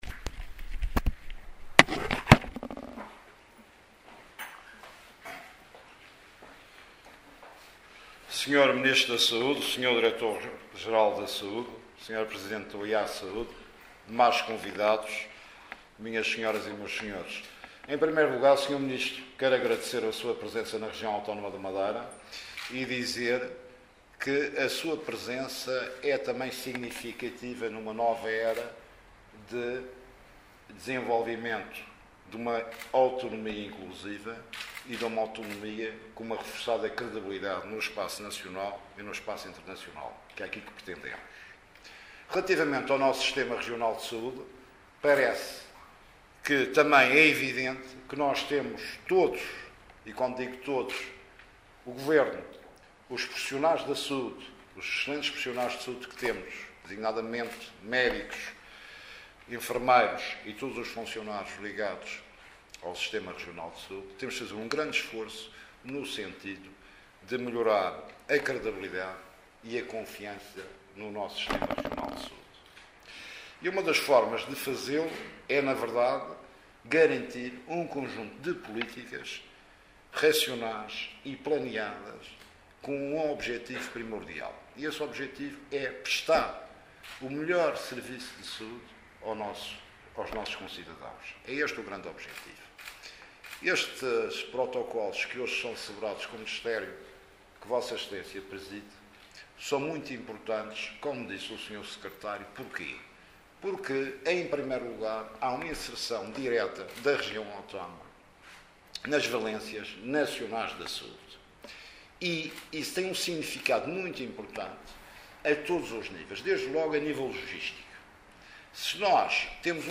Intervenção do Presidente do GR na cerimónia de assinatura dos memorandos de entendimento